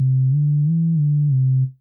Bass_07E.wav